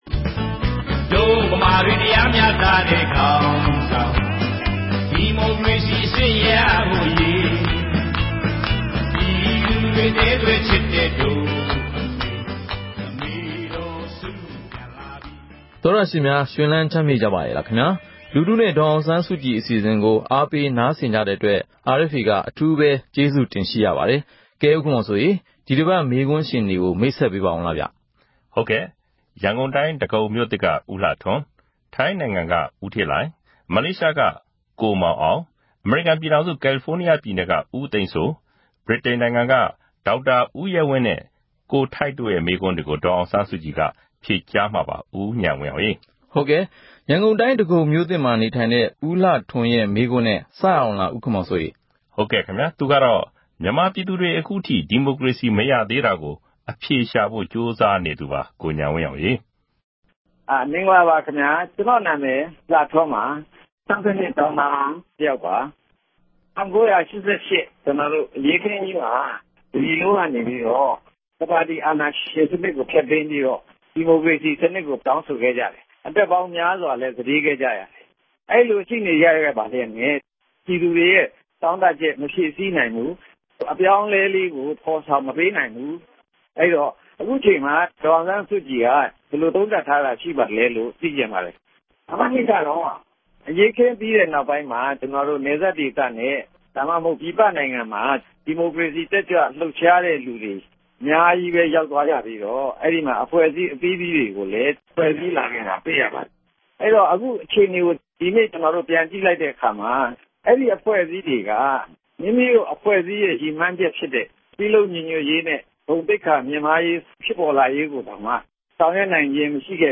‘လူထုနှင့် ဒေါ်အောင်ဆန်းစုကြည်’ အပတ်စဉ်အမေးအဖြေ